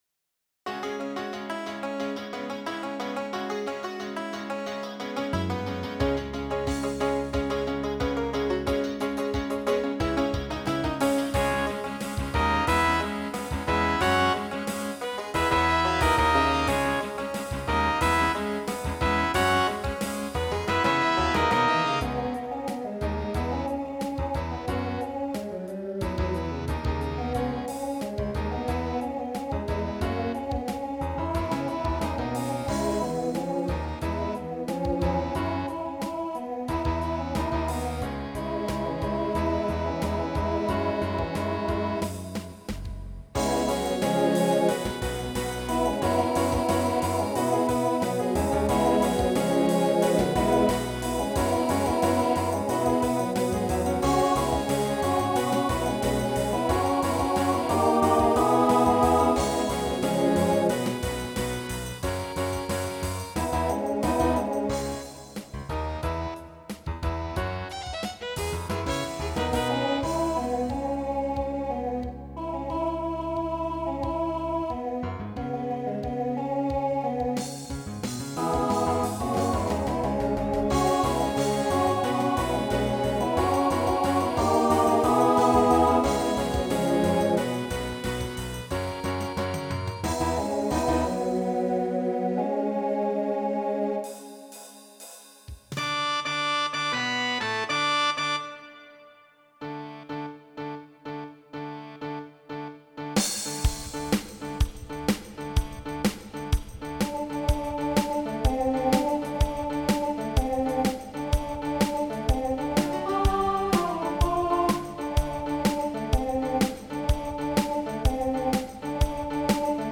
TTB/SSA
Voicing Mixed Instrumental combo Genre Country